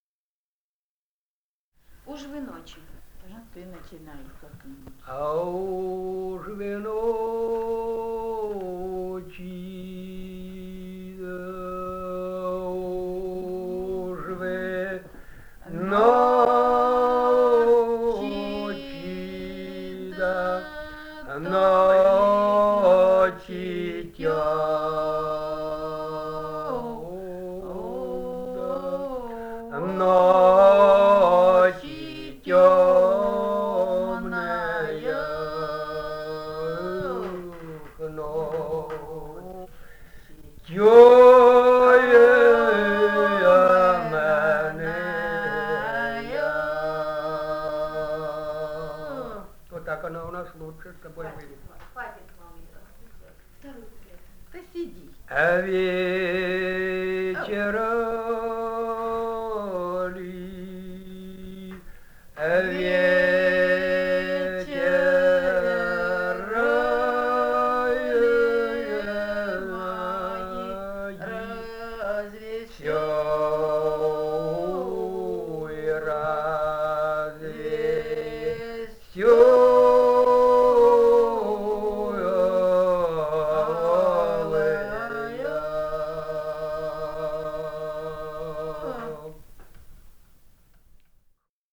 Этномузыкологические исследования и полевые материалы
«Уж вы ночи» (лирическая).
Алтайский край, с. Михайловка Усть-Калманского района, 1967 г. И1001-11